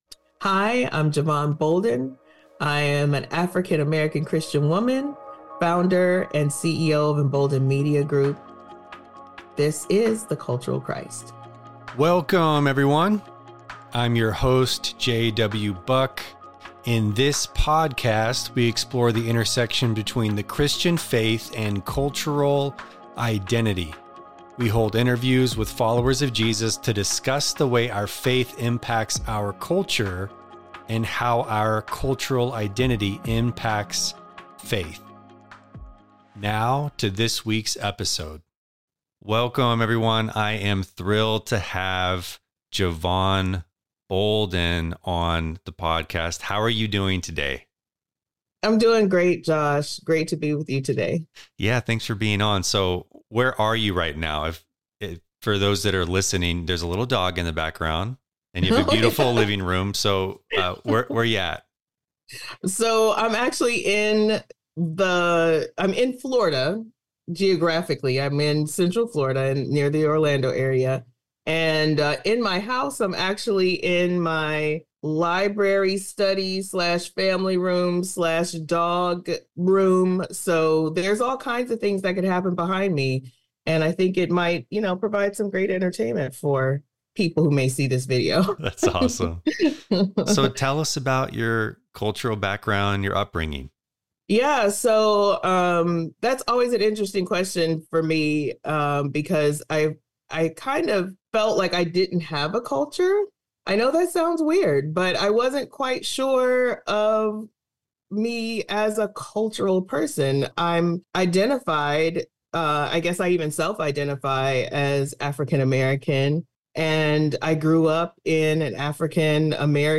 Join us next week for part two of this insightful conversation.